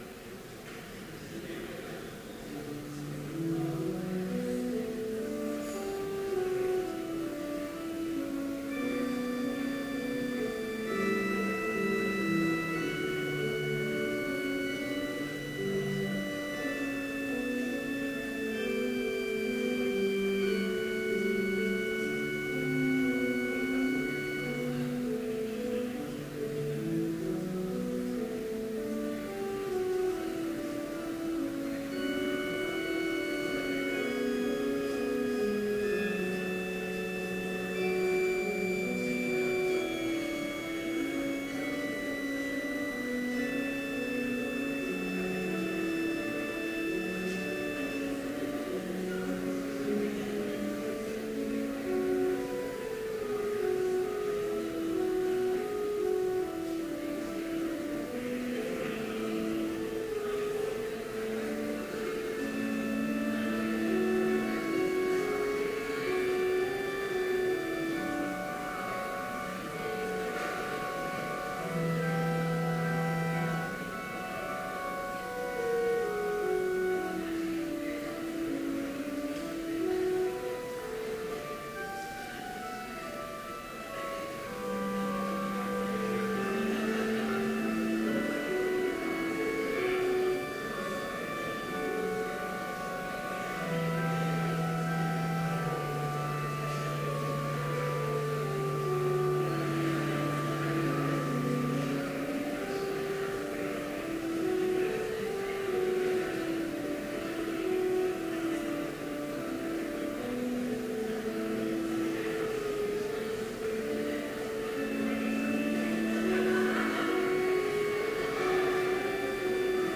Complete service audio for Chapel - September 18, 2014